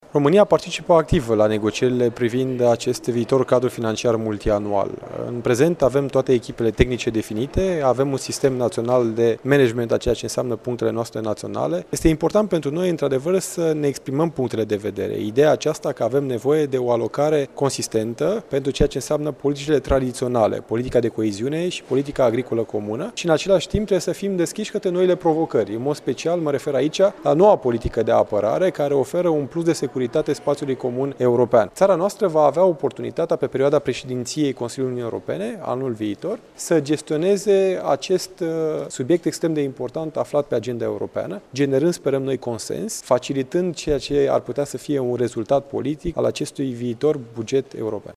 El participă la Universitatea Alexandru Ioan Cuza la Conferinţa Internaţională EUROINT, unde sunt prezenţi invitaţi din România, Republica Moldova şi Ucraina.